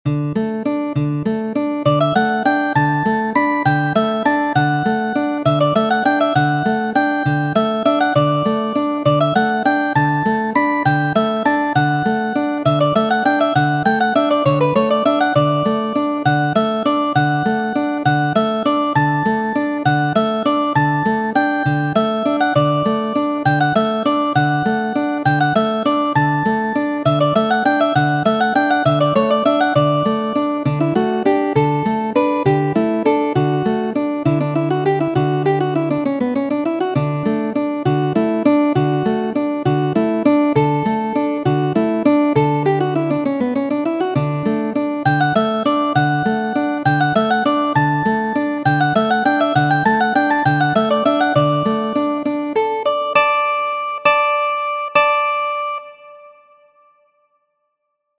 Saltarello, danco verkita de Vicenzo Galilei, patro de Galileo.